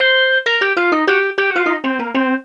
Swinging 60s 2 Organ Lk-C.wav